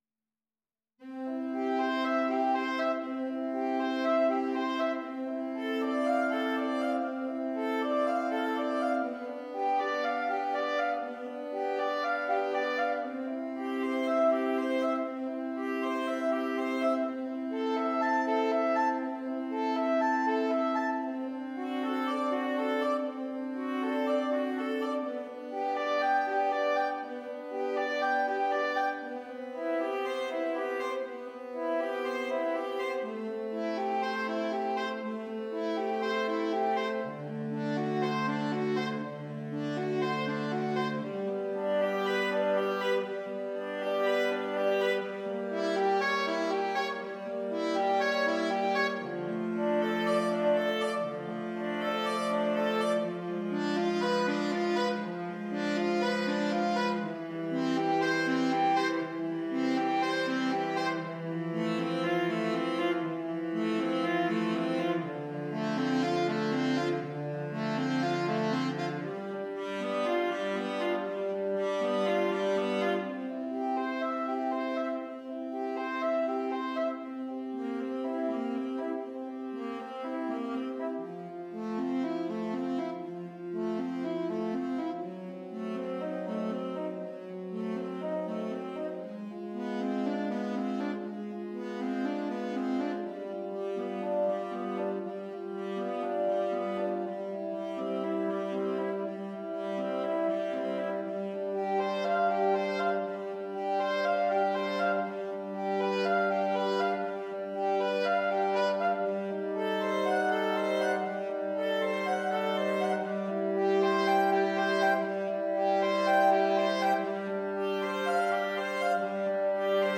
Saxophone Quartet (SATB)